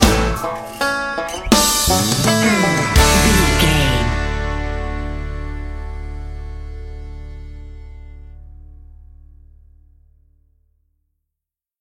Ionian/Major
drums
electric piano
electric guitar
bass guitar
banjo
country rock
high energy